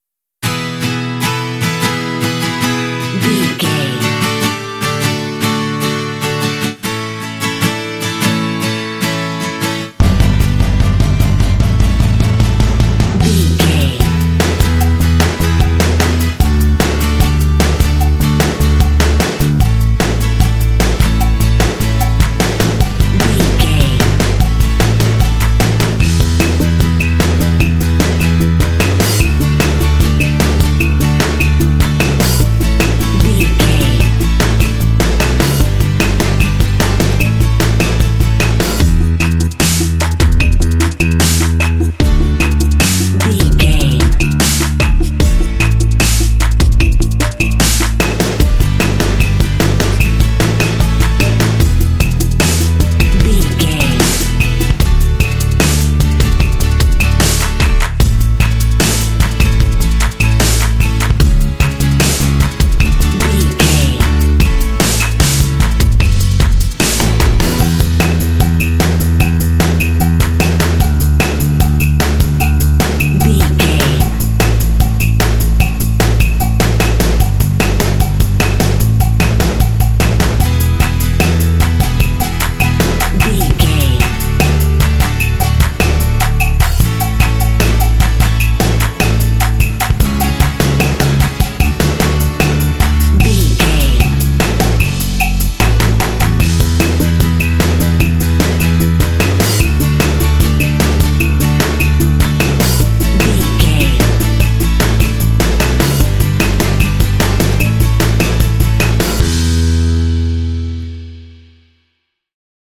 Uplifting
Ionian/Major
Fast
energetic
acoustic guitar
drums
percussion
bass guitar
playful
pop
contemporary underscore